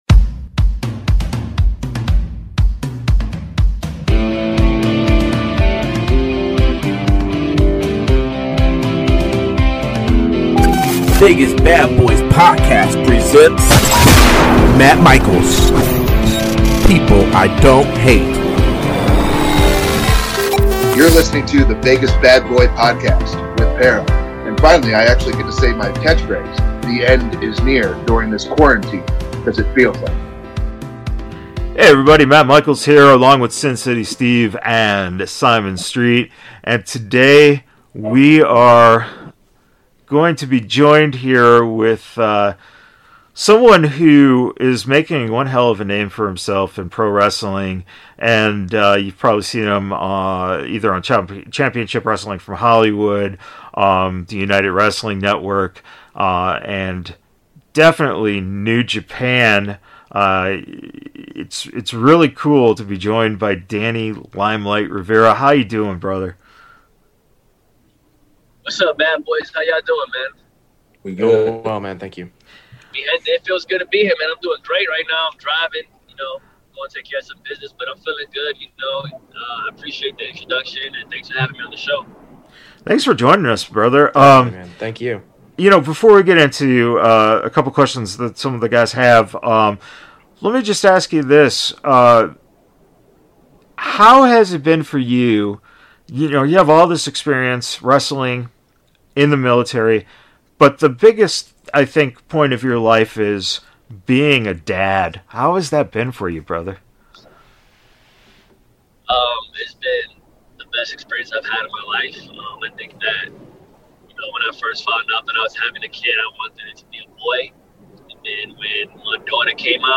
It’s an informative conversation with one of wrestling’s up and coming stars!